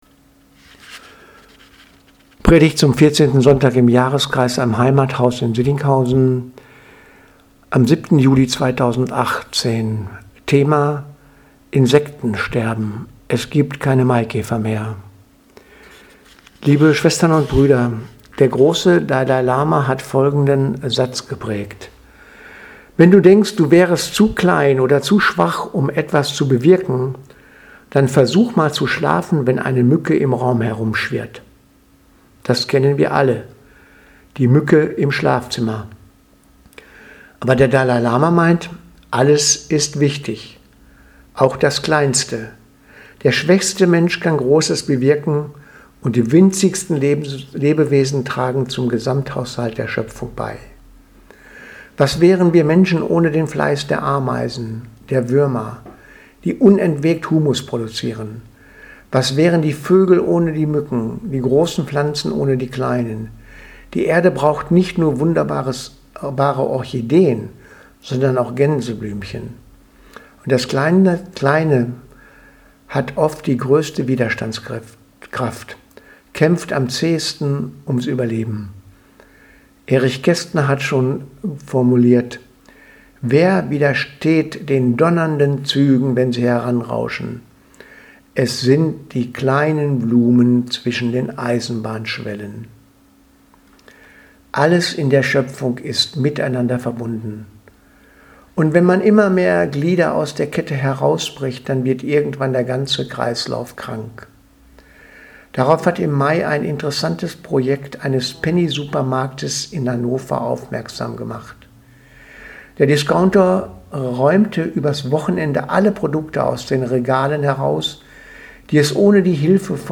Predigt vom 07.07.2018 Insektensterben